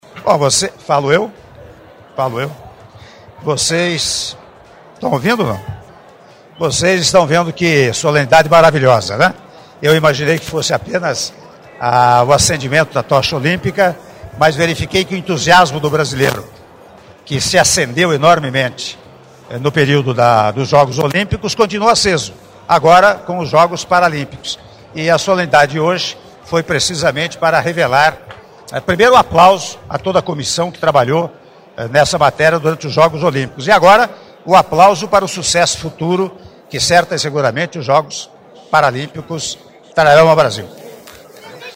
Áudio da entrevista coletiva concedida pelo Senhor Presidente da República em exercício, Michel Temer, após cerimônia de Recepção da Tocha Paralímpica - Brasília/DF (41s)